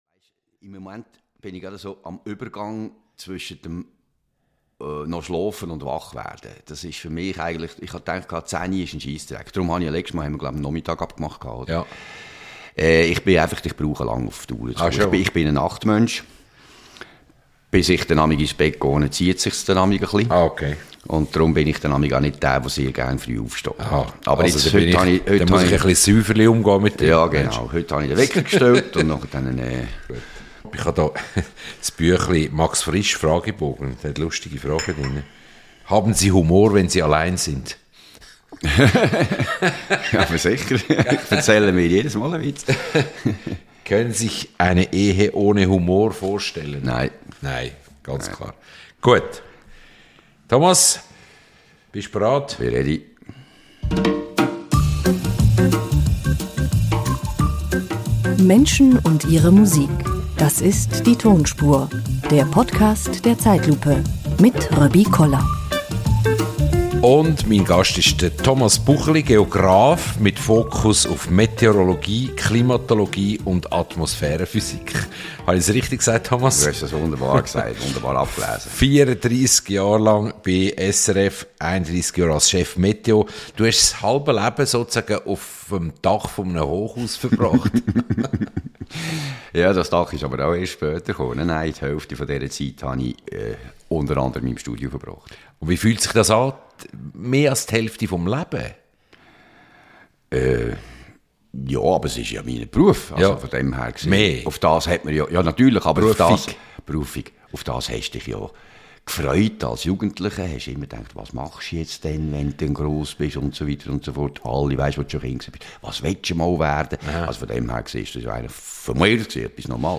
Der langjährige SRF-Meteo-Mann ist ein leidenschaftlicher Erzähler. Im Gespräch mit Röbi Koller schaut Thomas Bucheli kurz vor seiner Pensionierung auf sein Berufsleben zurück.